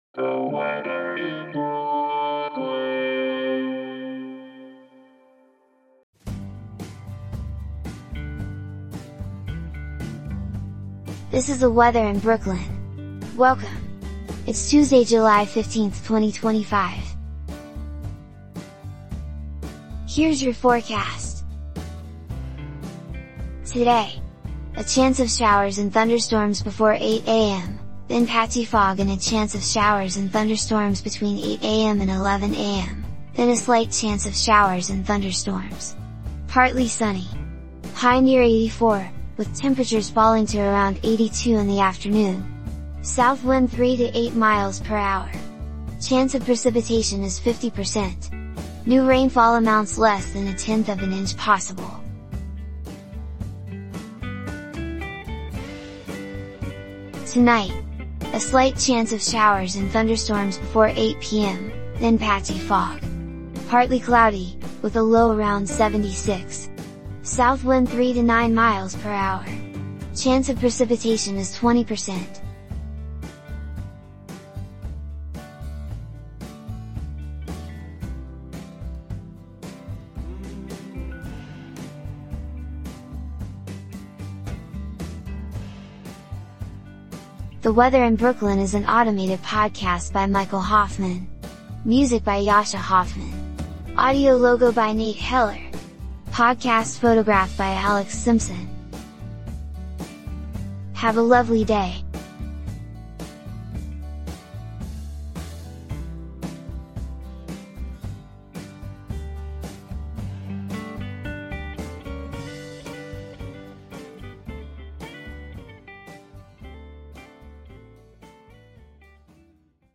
and is generated automatically.